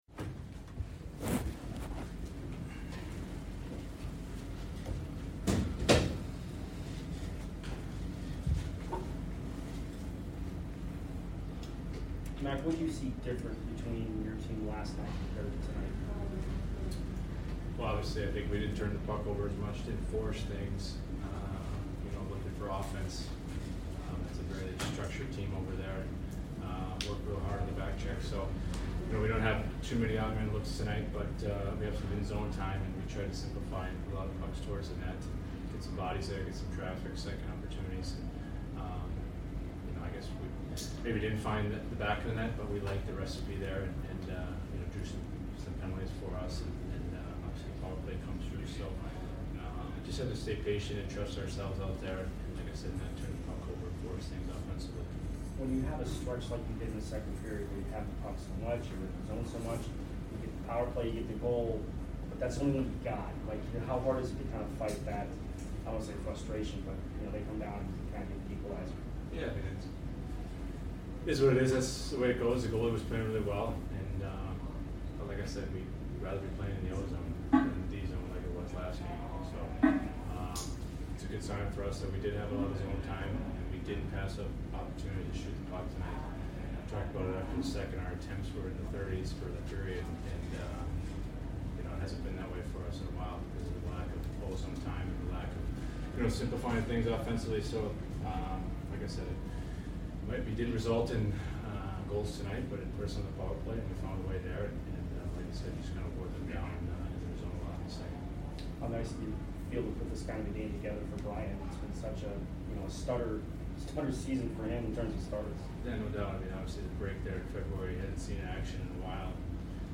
Ryan McDonagh Post Game Vs DET 3 - 4-2022